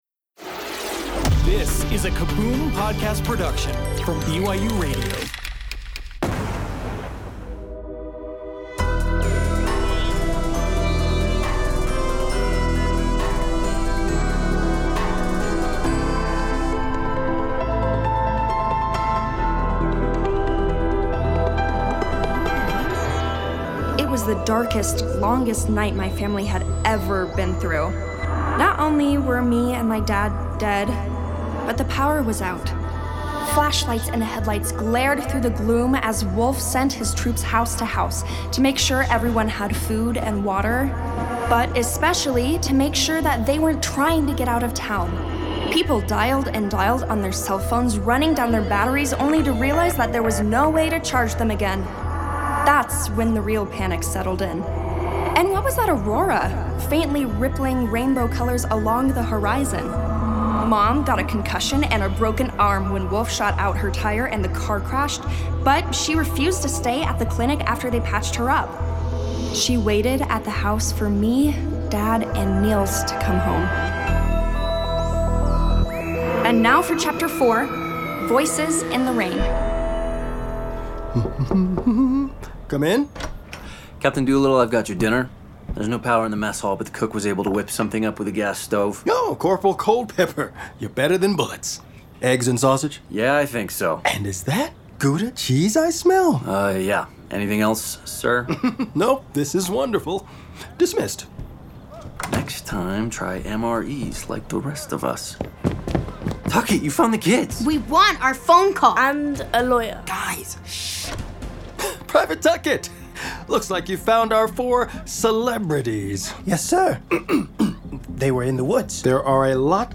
The whole family will love listening to these original fiction stories together, which feature full casts of talented voice actors and cinematic sound design. Each episode is its own complete story, so jump in anywhere you like.